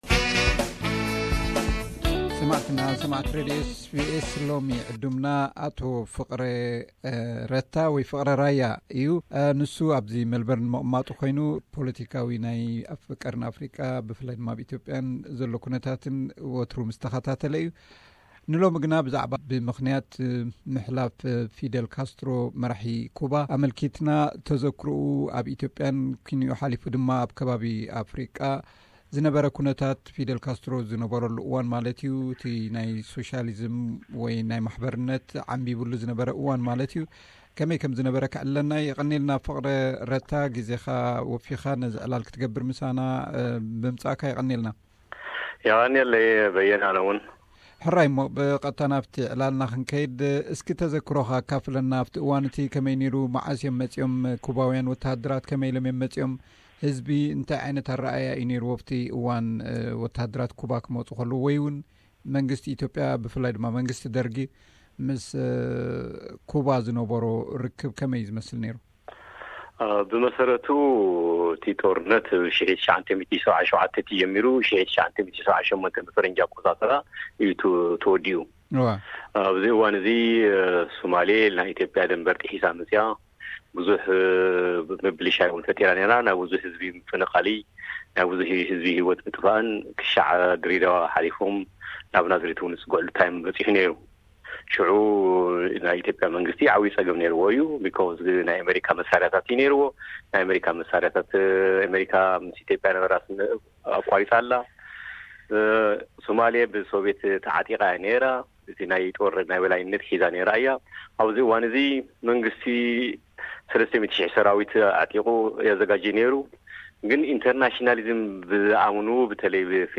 ቃለ ምሕትት። እዚ ብምኽንያት ዝኽሪ ልደት 50 ዓመት ምጅማር ኤስ ቢ ኤስ ዳግማይ ዝቐረበ እዩ።